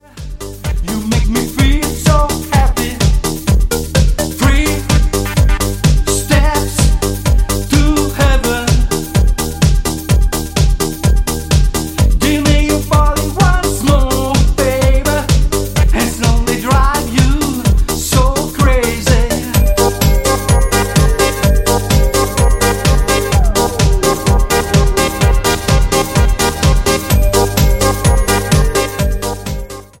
DANCE  (03.25)